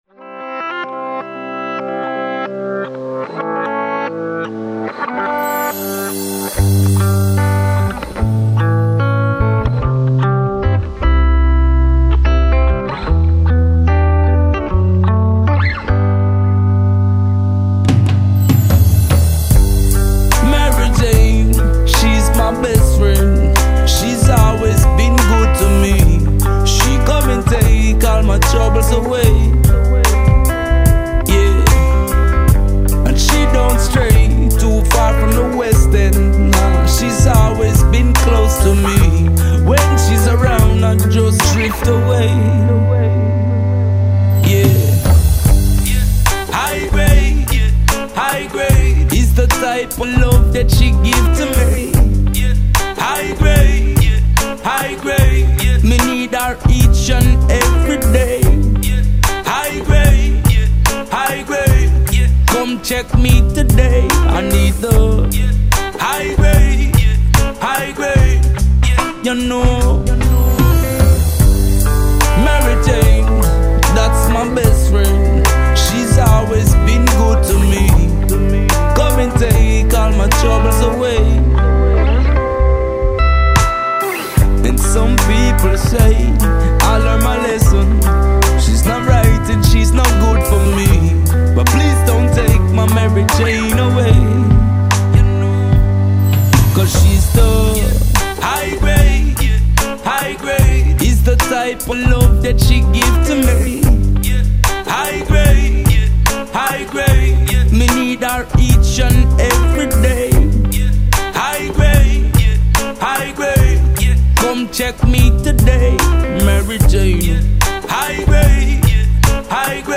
If you like pure Reggae, i think this qualifies.